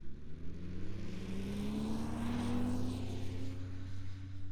Internal Combustion Snowmobile Description Form (PDF)
Internal Combustion Subjective Noise Event Audio File - Run 3 (WAV)